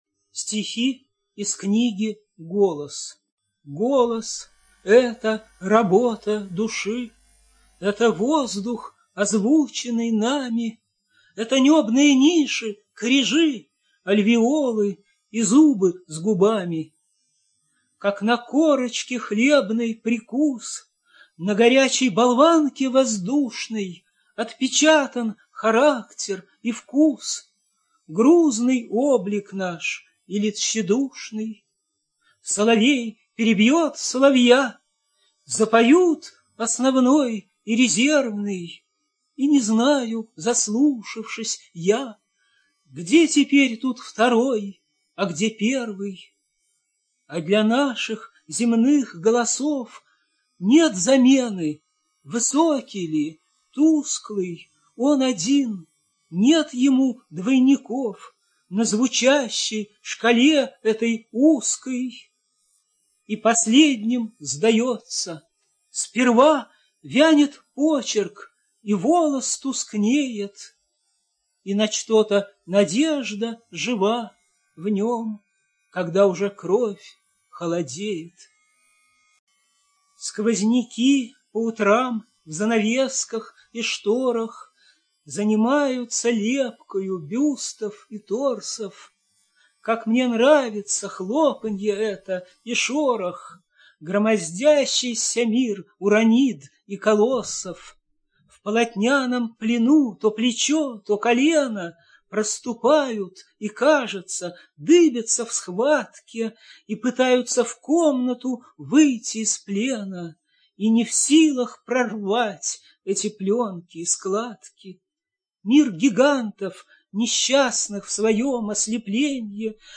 ЧитаетАвтор
ЖанрПоэзия
Кушнер А - Новое дыхание. Сборник стихов (Автор)(preview).mp3